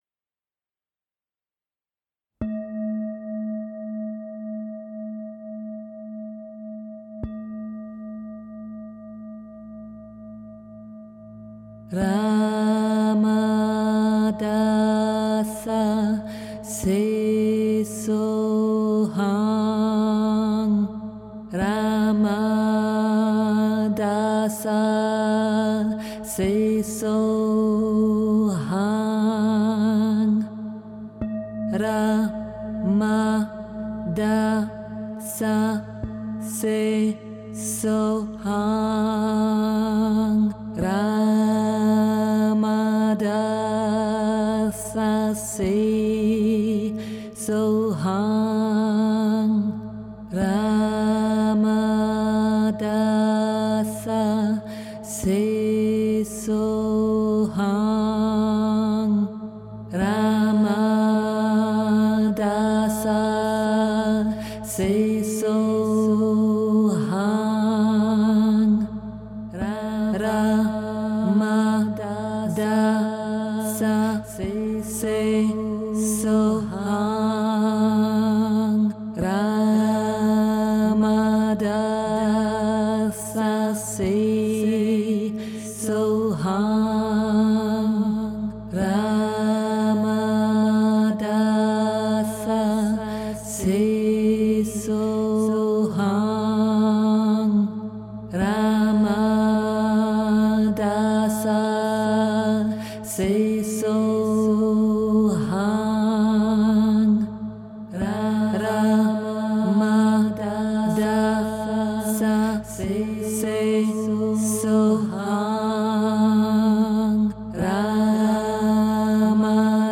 sehr gefühlvoll gespielten Piano aufgenommen haben